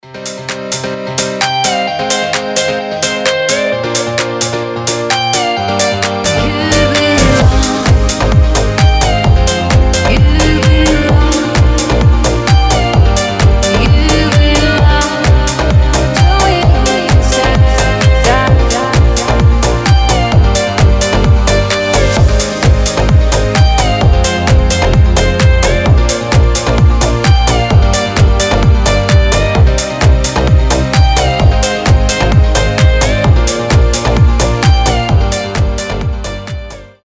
• Качество: 320, Stereo
клавишные
танцевальная музыка
Style: house/dance